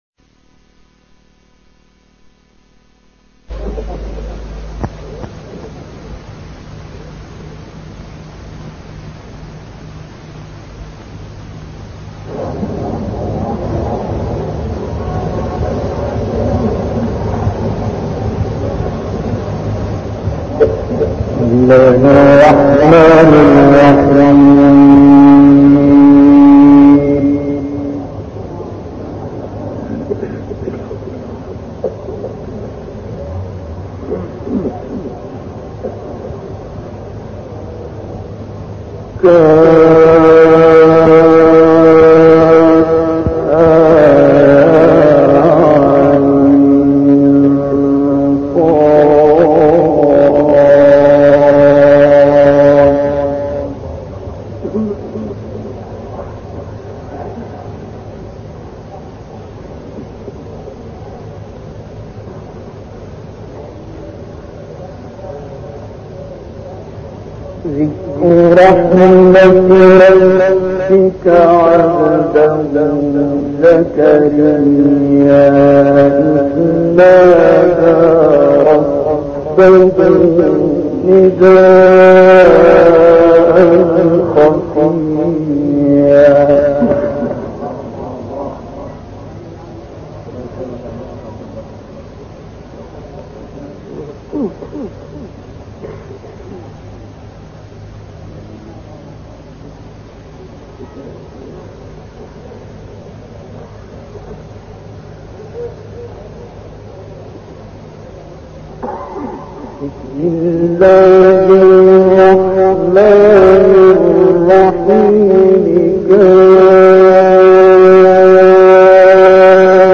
INDONESIA (IQNA) - Kalian mendengar lantunan tilawah Abdul Basit Muhammad Abdus Samad di negara Indonesia pada tahun 1956 M.
Menurut laporan IQNA, tilawah surah Maryam ayat 1 – 36 dengan suara Abdul Basit Muhammad Abdus Samad, qori terkemuka dunia Islam telah dipublikasikan di canel Telegram Abdul Basit.
Tilawah ini dilantunkan pada tahun 1956 M di negara Indonesia dan di hadapan Mohammed Shiddiq al-Minshawi, dengan durasi 45 menit.